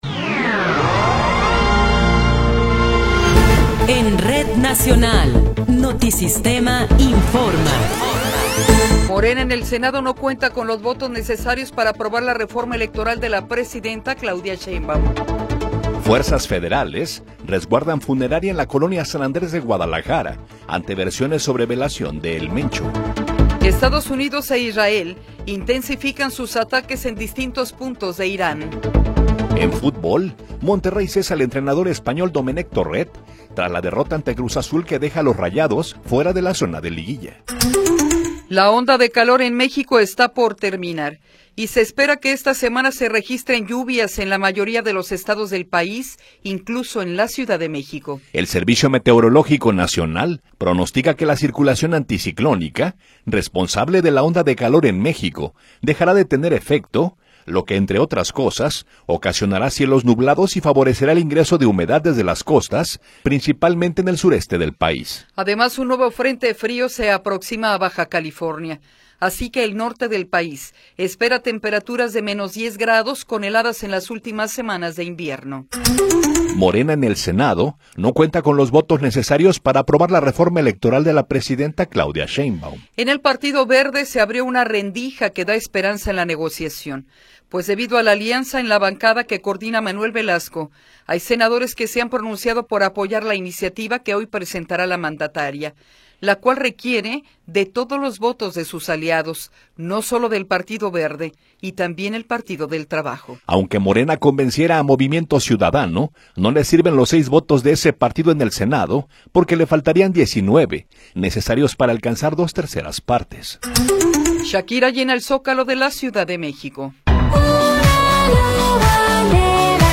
Noticiero 8 hrs. – 2 de Marzo de 2026